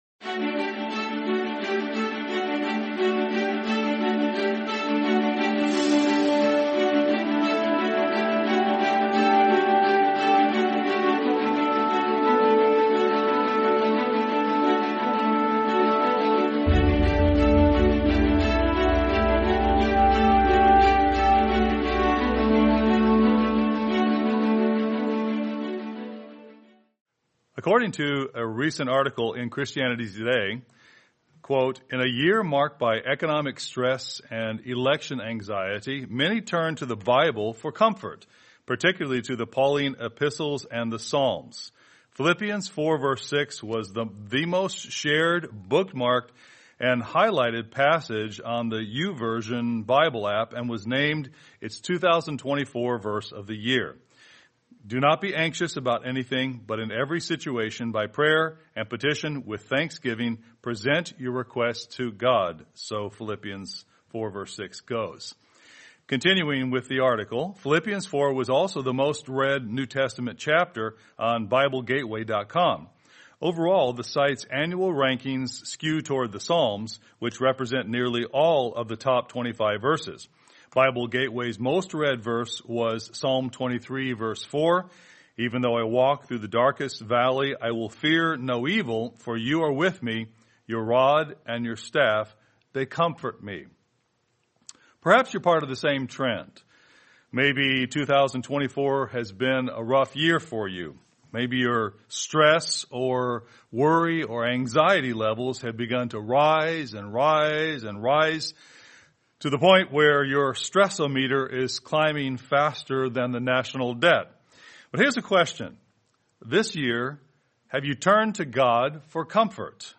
God of Comfort | Sermon | LCG Members